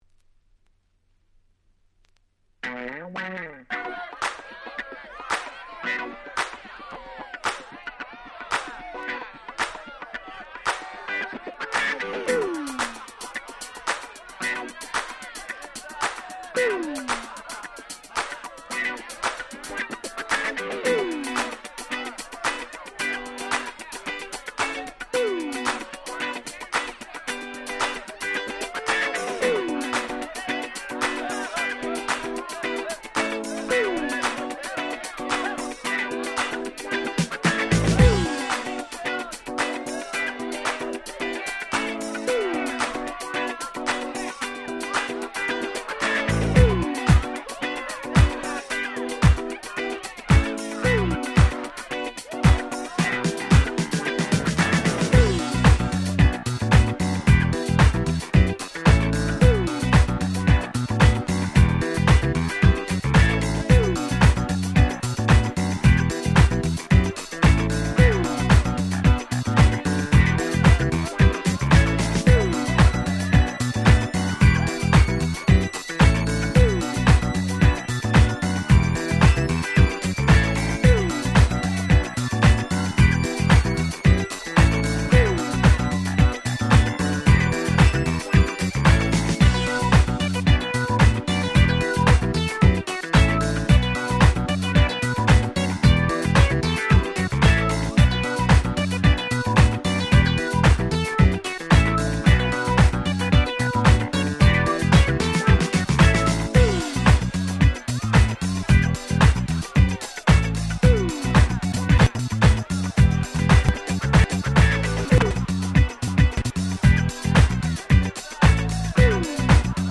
96' Smash Hit Dance Pop !!